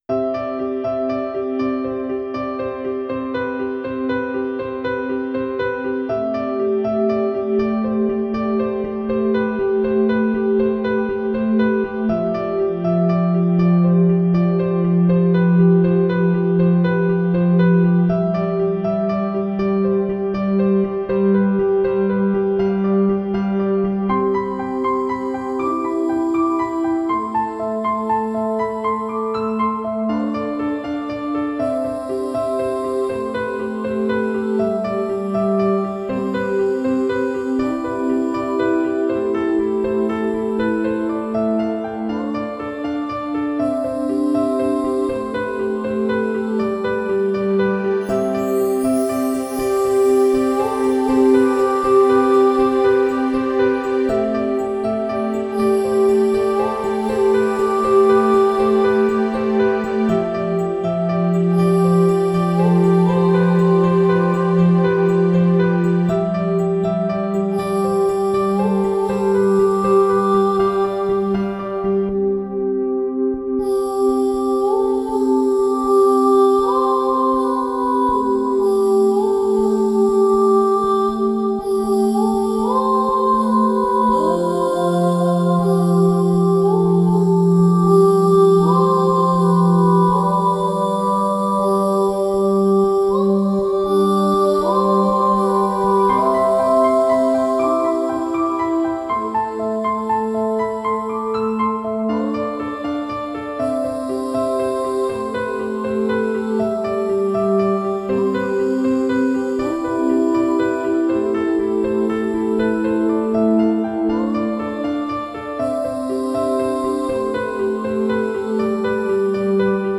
Genere: New Age.